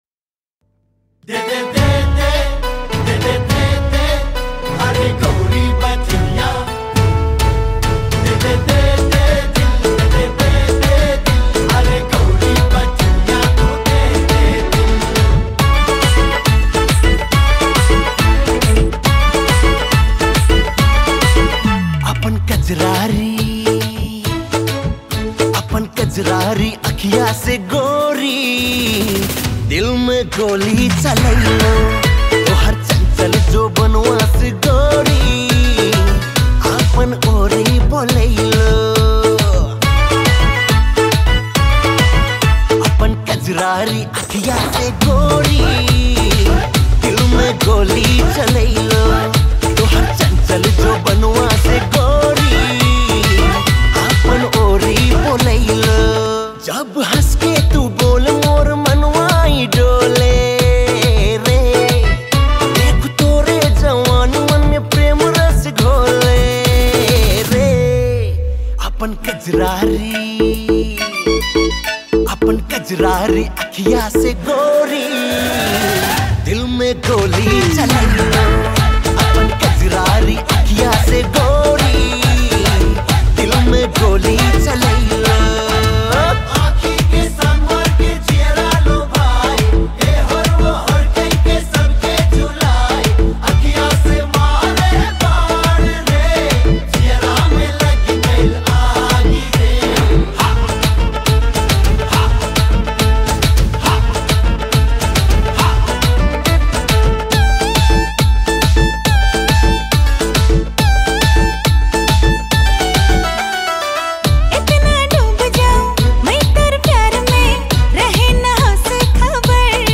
New Tharu Mp3 Song
soulful voices